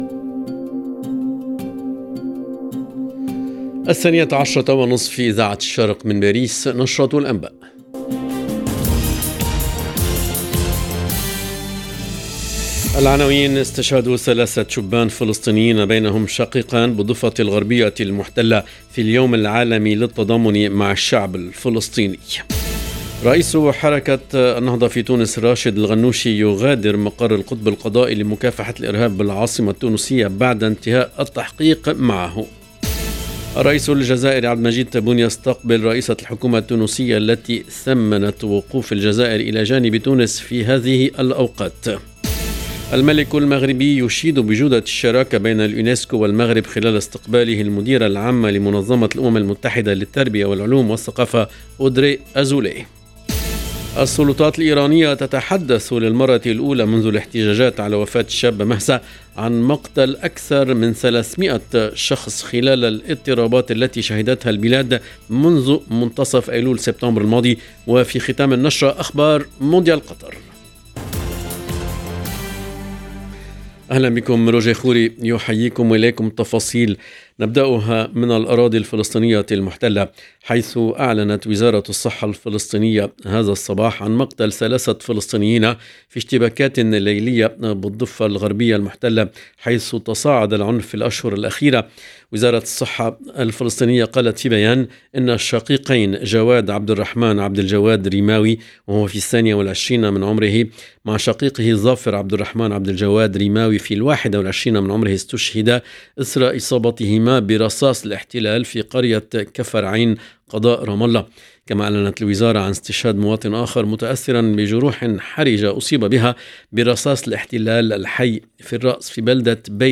LE JOURNAL DE MIDI 30 EN LANGUE ARABE DU 29/11/22